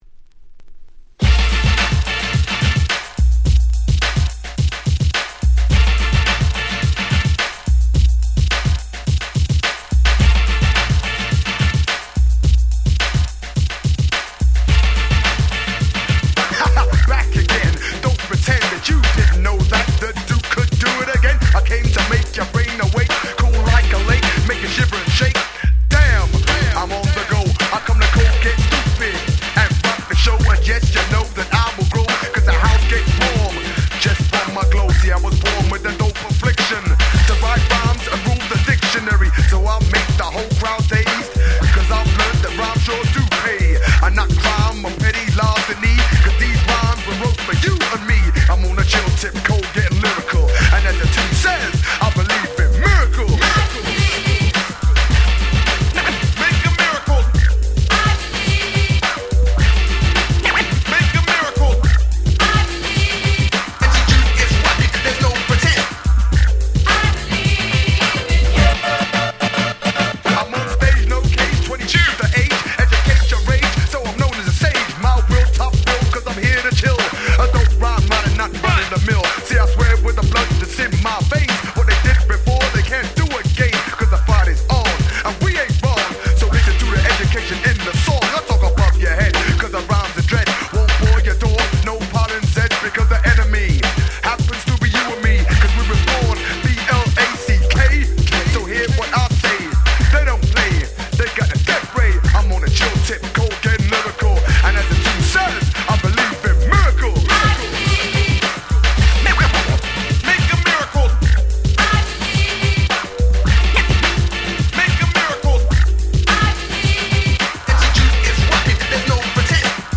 88' Nice Middle School Rap !!
大ネタ使いではありますが決しておちゃらけた感じのParty Rapって訳ではないんですよね。